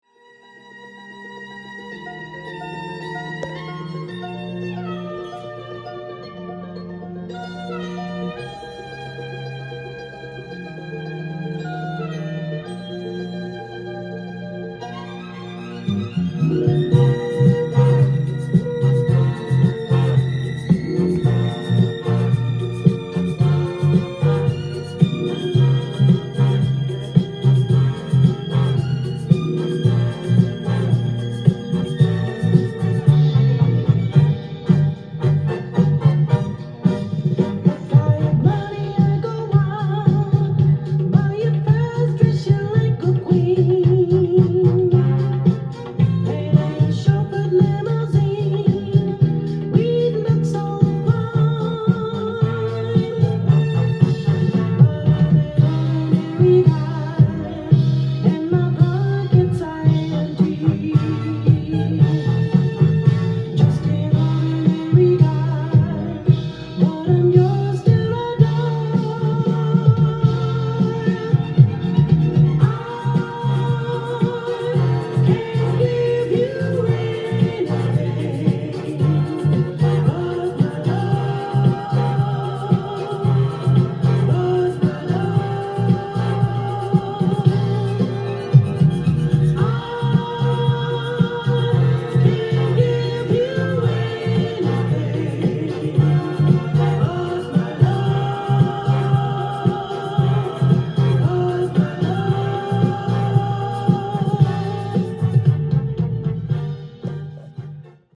ジャンル：SOUL-SALE
店頭で録音した音源の為、多少の外部音や音質の悪さはございますが、サンプルとしてご視聴ください。